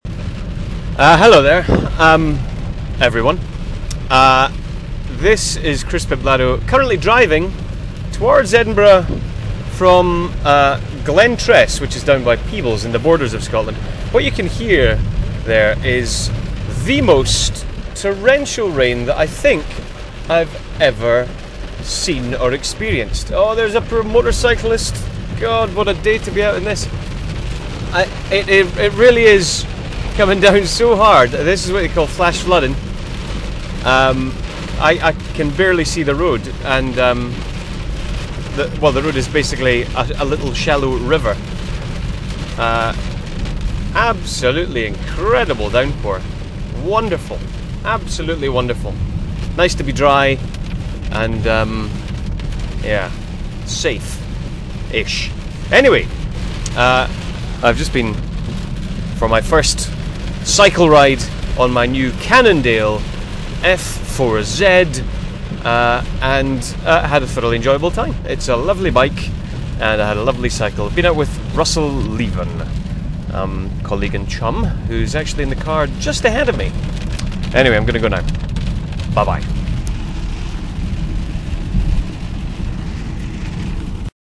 Raining At Glentress
30168-raining-at-glentress.mp3